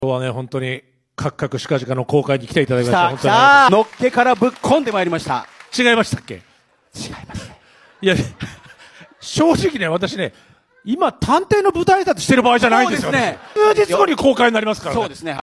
大泉洋、永野芽郁の主演作をぶっ込む 「探偵はBARー」舞台挨拶で